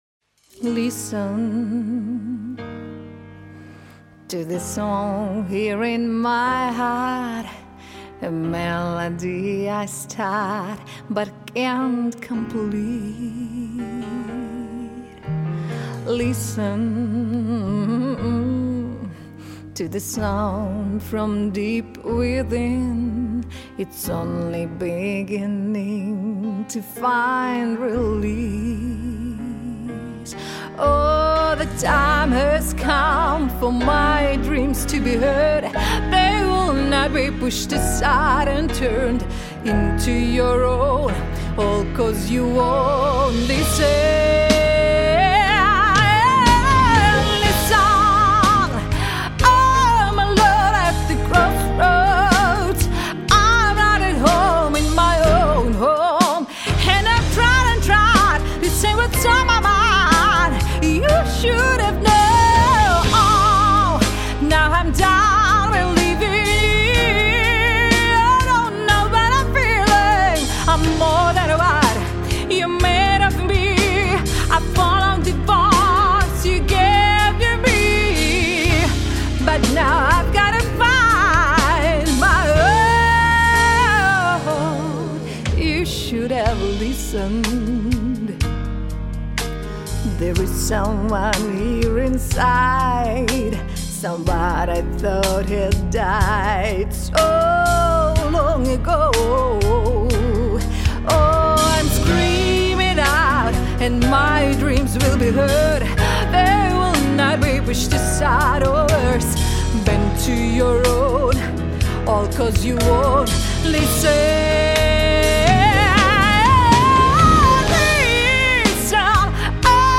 Сопрано Меццо-сопрано